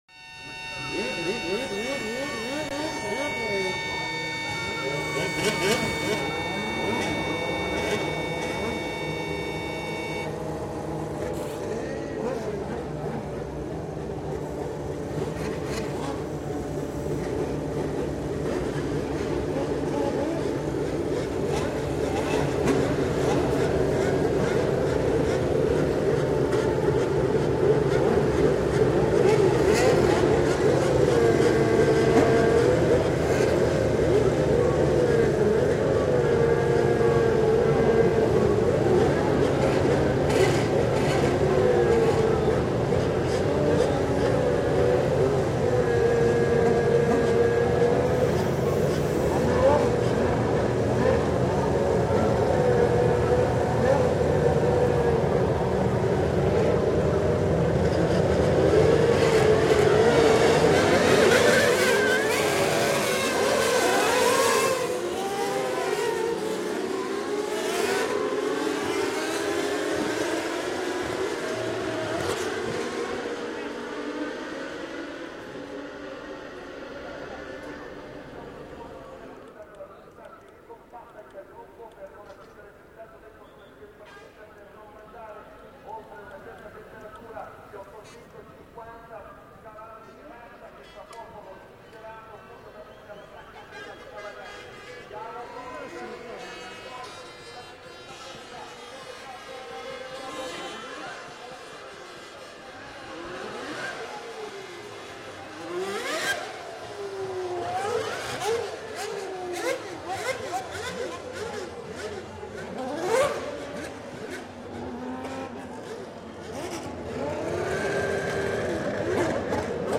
La partenza: Il lavoro dei meccanici sulla PitLane e poi Via ....